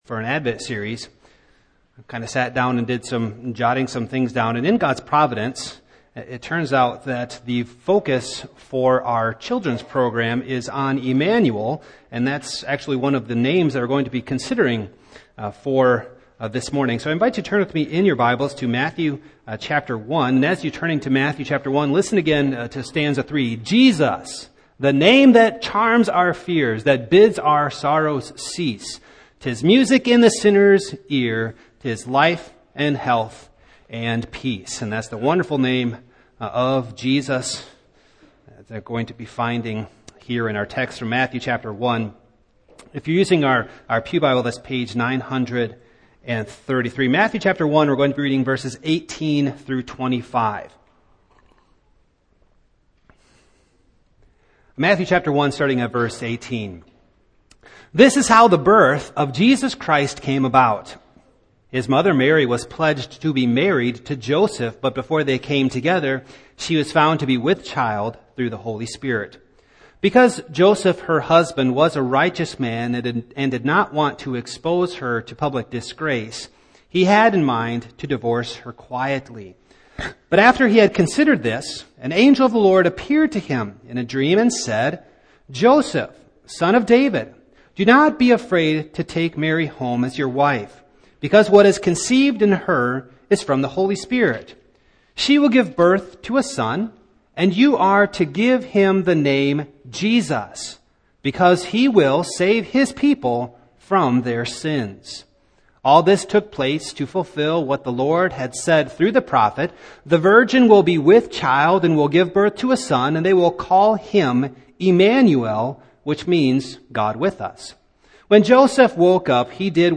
Sermons
Service Type: Morning